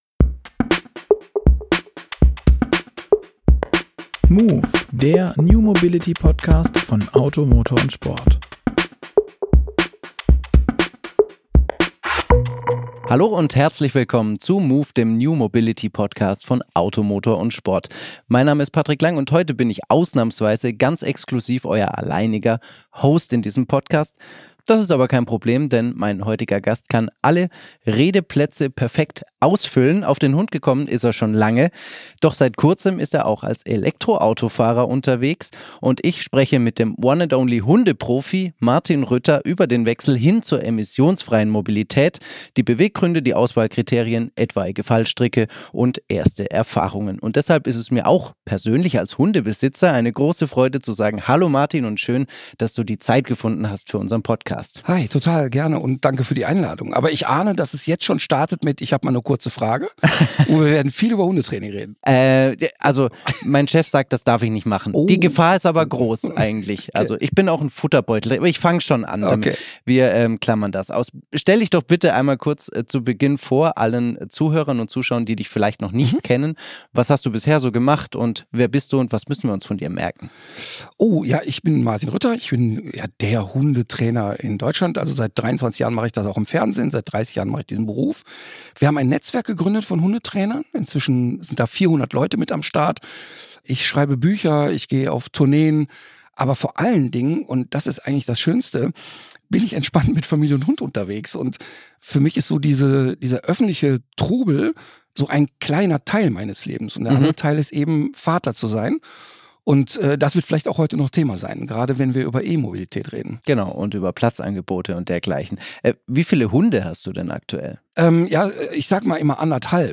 Moove | E-Auto und Hund - passt das? Wir fragen Hundeprofi Martin Rütter